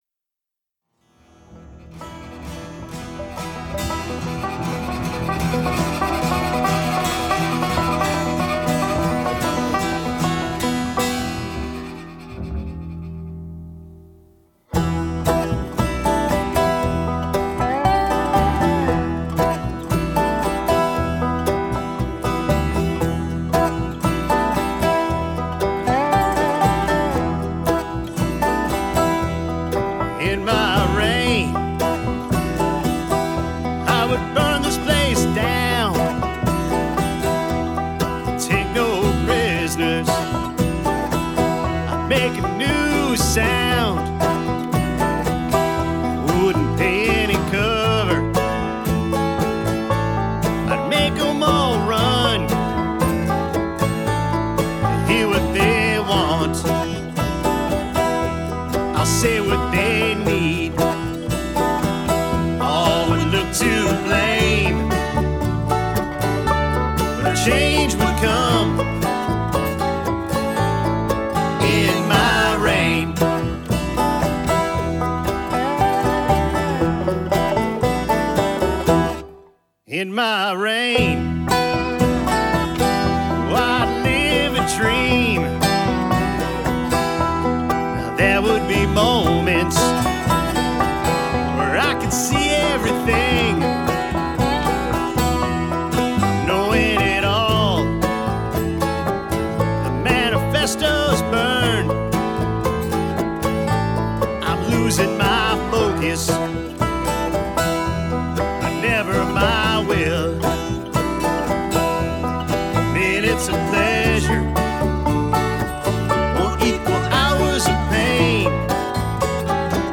Genre: Acoustic.